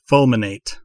Ääntäminen
IPA : /ˈfʌlmɪneɪt/